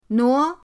nuo2.mp3